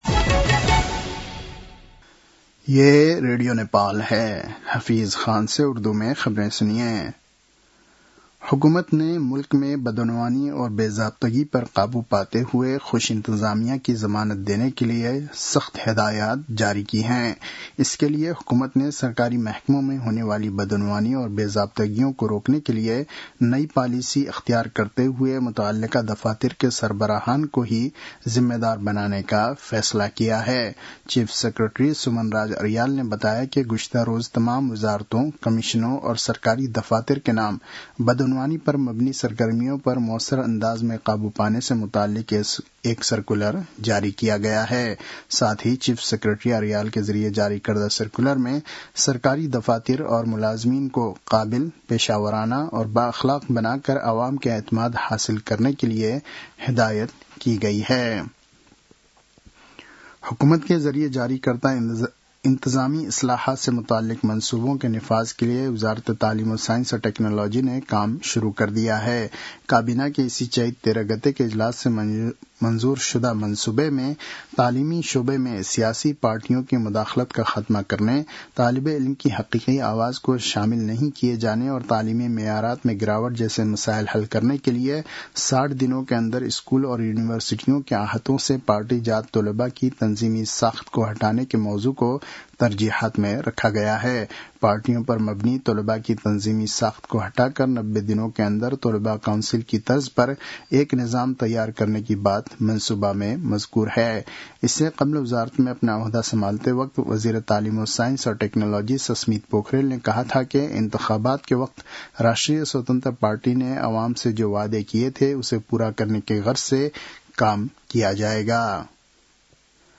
उर्दु भाषामा समाचार : २० चैत , २०८२
Urdu-news-12-20.mp3